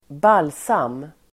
Uttal: [²b'al:sam]